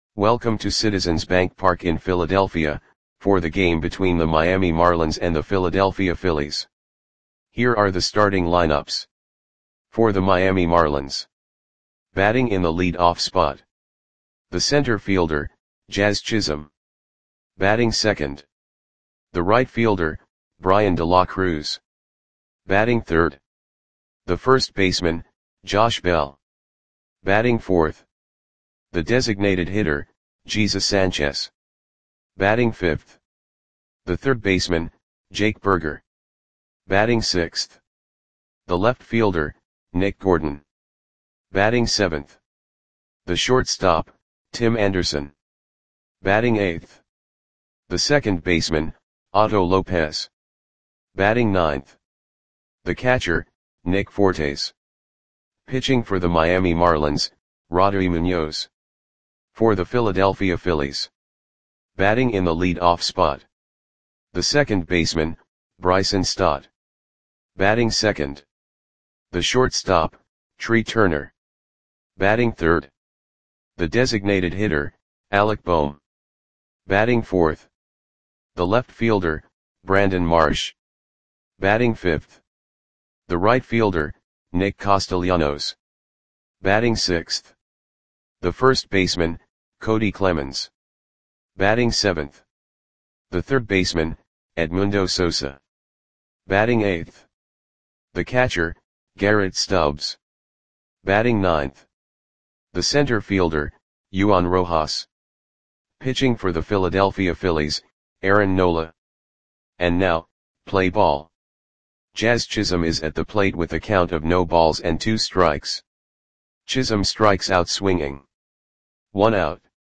Audio Play-by-Play for Philadelphia Phillies on June 29, 2024
Click the button below to listen to the audio play-by-play.